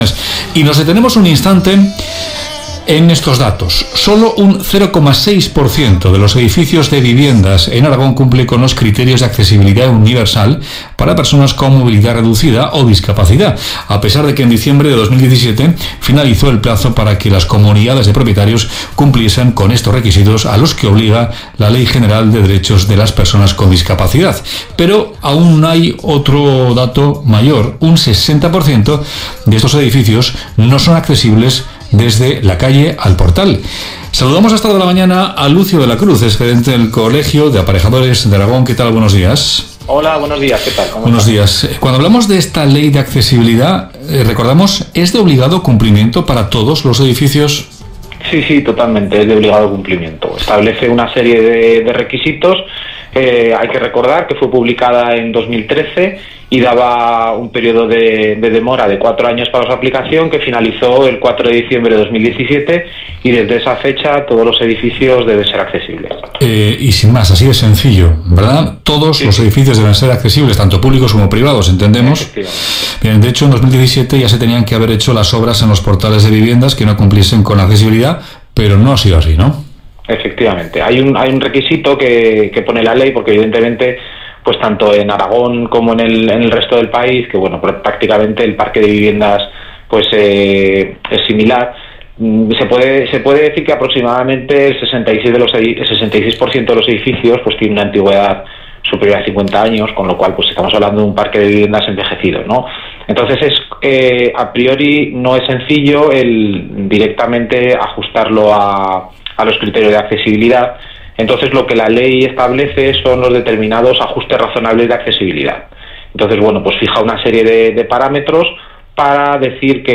Aquí os dejamos la síntesis de la conversación mantenida donde se resuelve esta incógnita sobre la accesibilidad fruto de tantas discusiones vecinales.